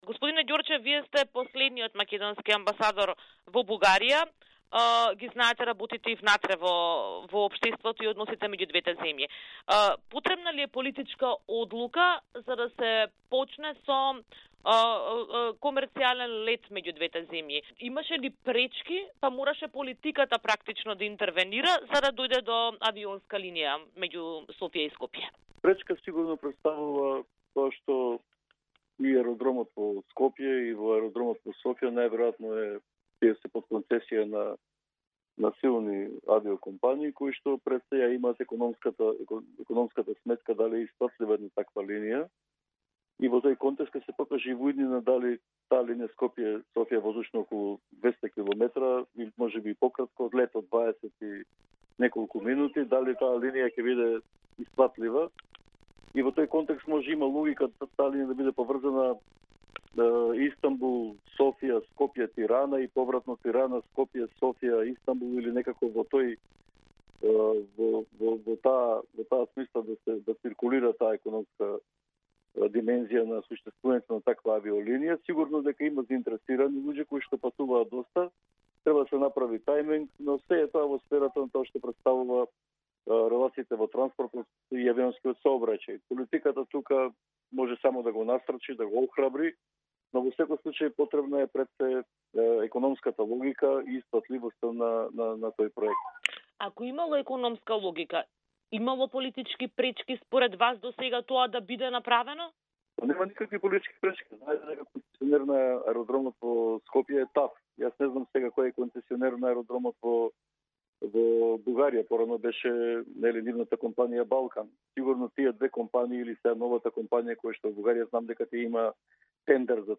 Последниот амбасадор на Македонија во Бугарија Марјан Ѓорчев, кој на позицијата беше до 2020 година, во изјава за Радио Слободна Европа вели дека и досега немало никакви политички пречки да се воспостави авиолинијата.